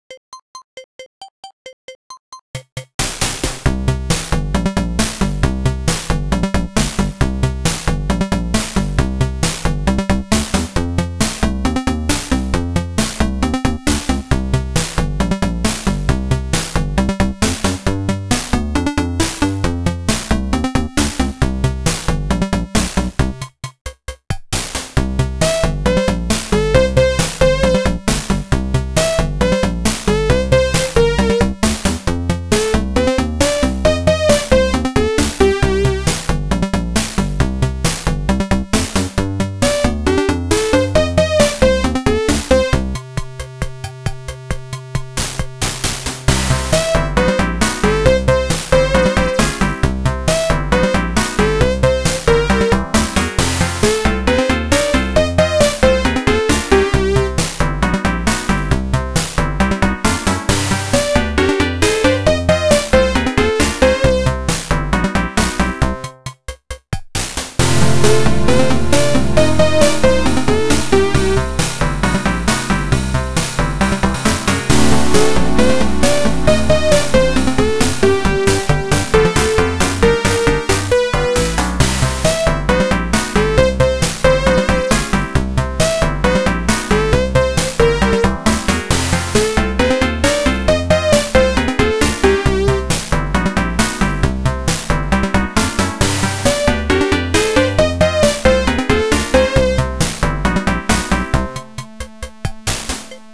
Silly upbeat blues tune